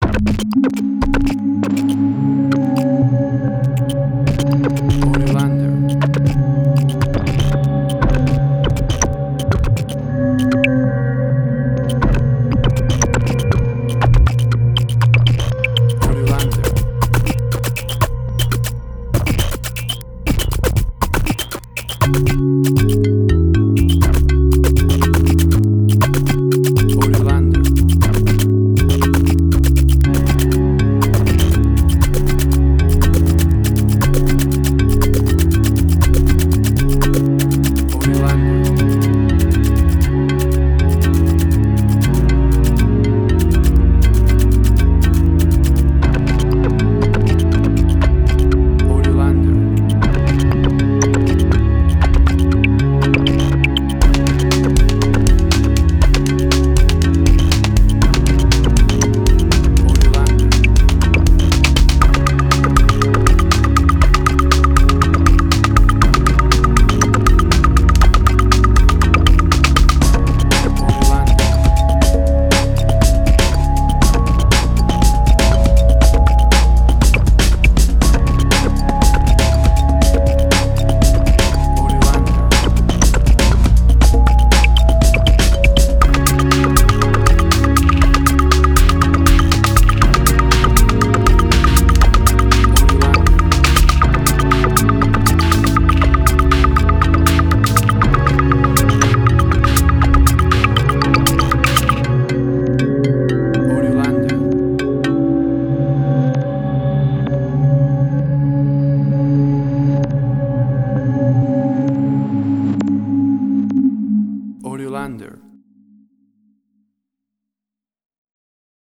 IDM, Glitch.
Tempo (BPM): 120